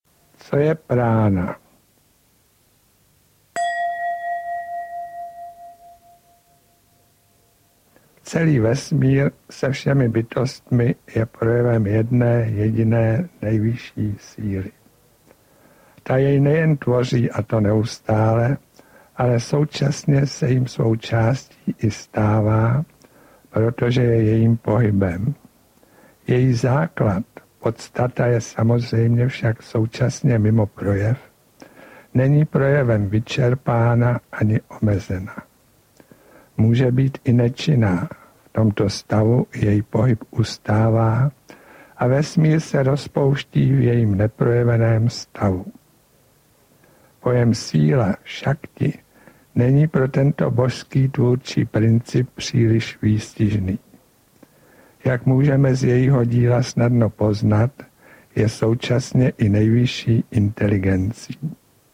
Vnitřní pránájáma, výklad a praktická ukázka cvičení. Hovory č. 5 jsou namluveny tak, že je podle nich možno cvičit vnitřní pránájámu. Nejedná se o studiovou nahrávku.